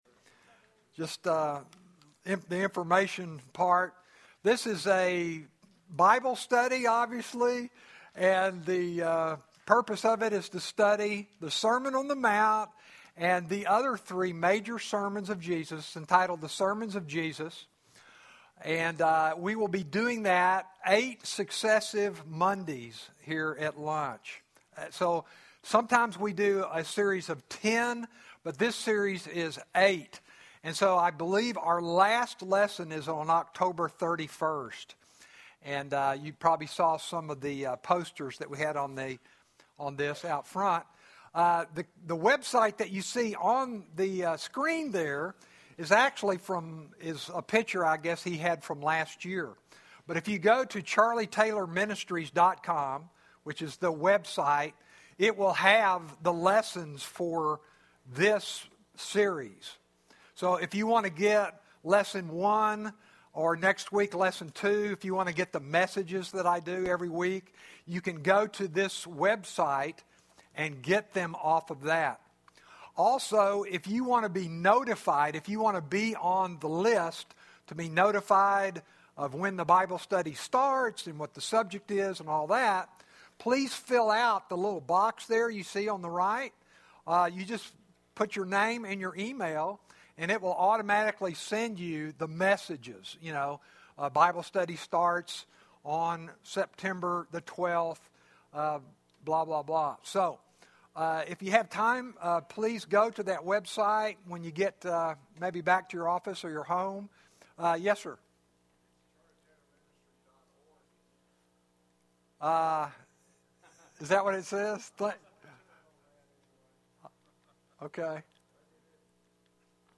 Bible Study: The Sermons of Jesus Fall 2011 Bible Study